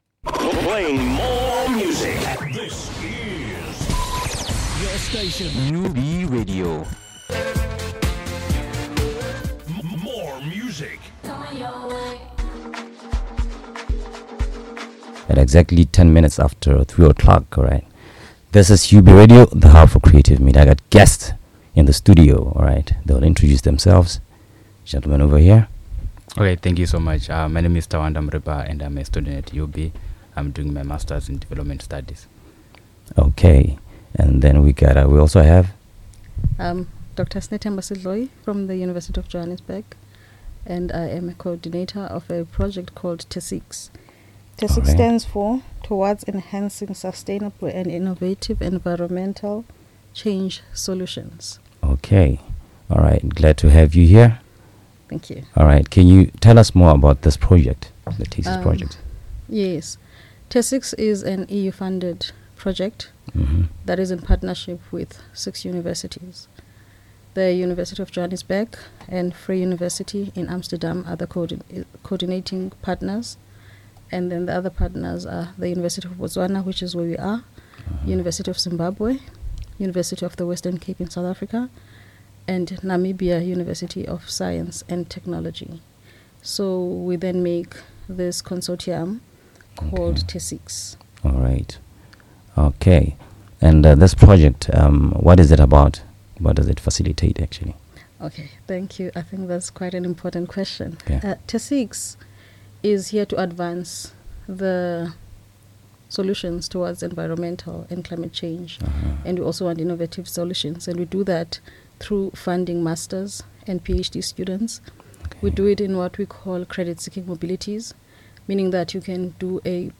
Listen below to learn more about the TESIECS project and how it can benefit you. Also hear some great feedback from a student beneficiary
TESIECS-INTERVIEW-1.mp3